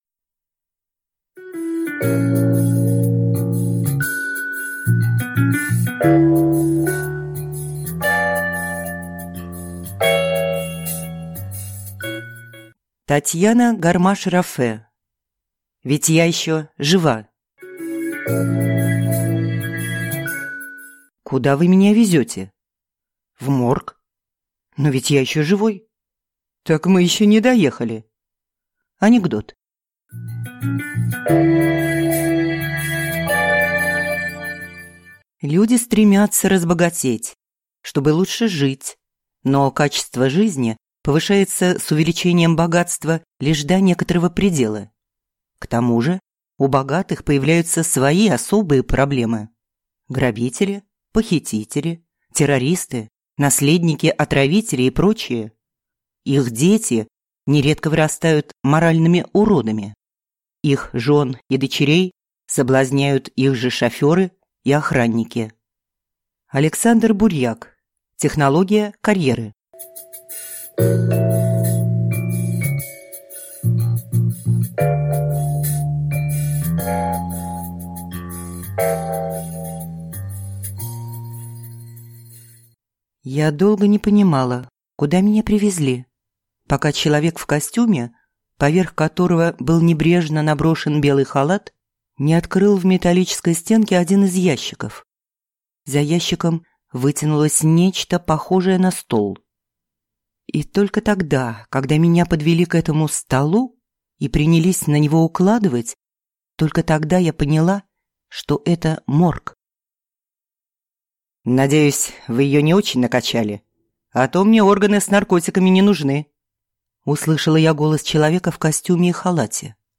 Аудиокнига Ведь я еще жива | Библиотека аудиокниг